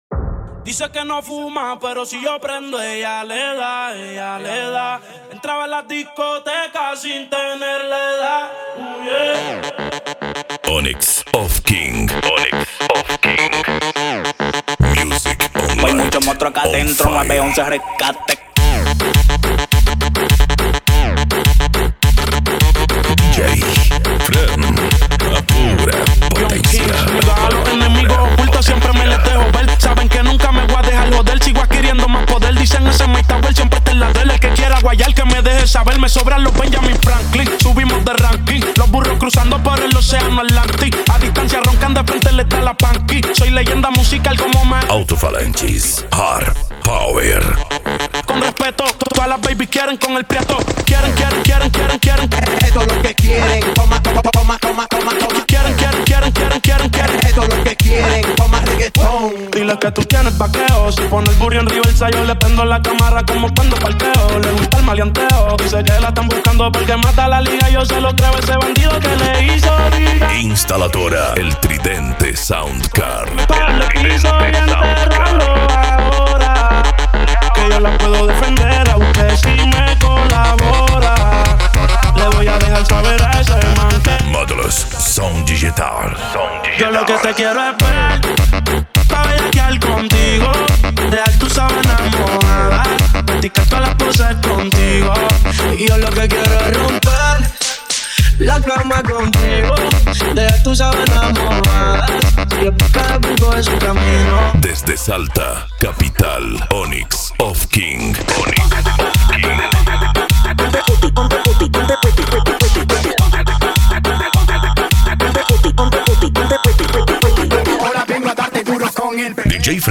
Bass
Modao
Musica Electronica
Remix